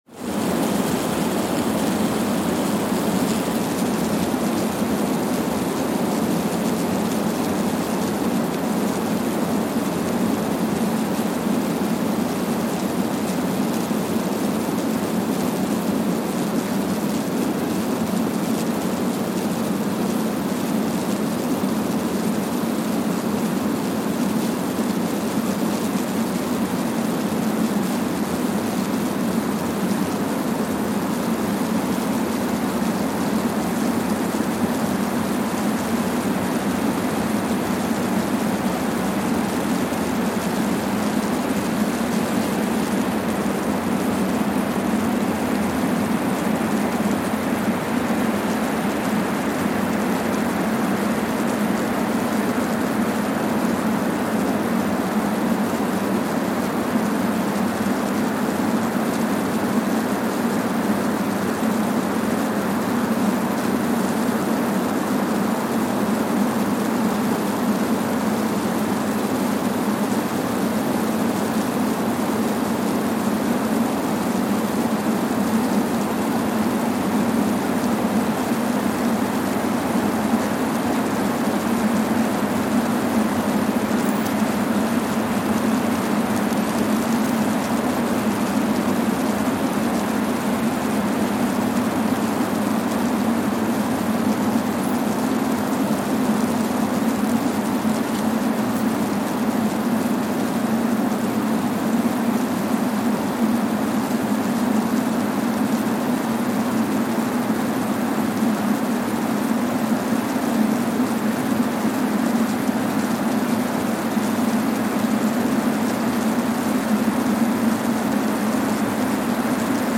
Kwajalein Atoll, Marshall Islands (seismic) archived on July 14, 2023
Station : KWJN (network: IRIS/IDA) at Kwajalein Atoll, Marshall Islands
Sensor : Streckeisen STS-5A Seismometer
Speedup : ×1,000 (transposed up about 10 octaves)
Loop duration (audio) : 05:45 (stereo)